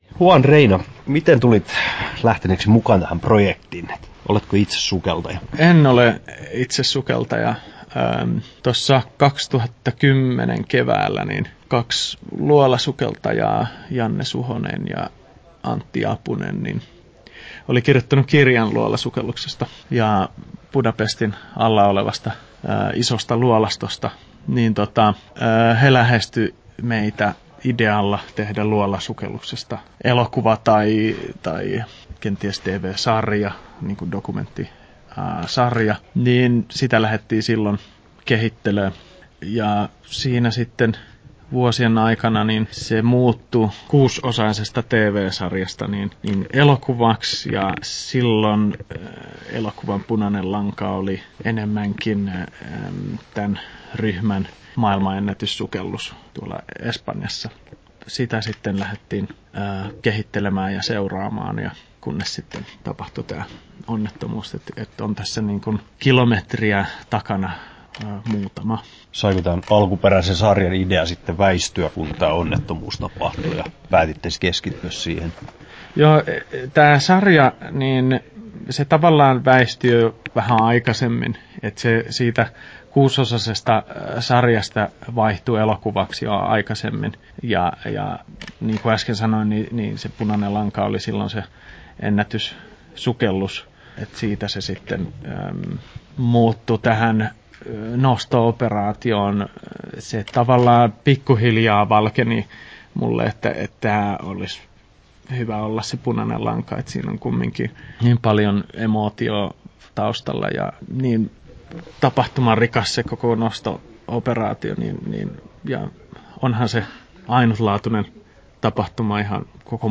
Haastattelussa
12'54" Tallennettu: 9.2.2016, Turku Toimittaja